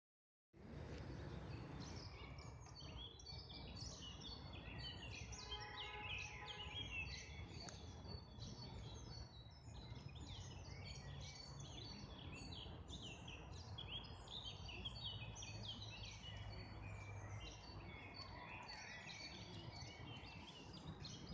oiseaux
birds.mp3